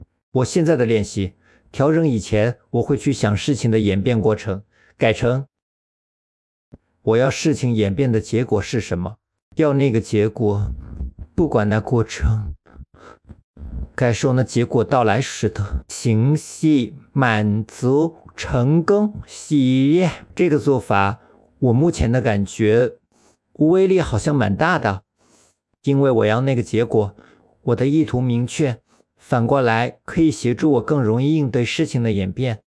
用 Claude Code, 經由 Gemini 的幫忙，建立一個 TTS 文字轉語音平台，使用 CosyVoice 3.0，可以複製聲音，加入情緒控制。
這個是音色複製，錄一段聲音，讓系統學習，就可以模仿出相類似的聲音。
以下是測試的範例：大陸腔有點重，找時間再來調整。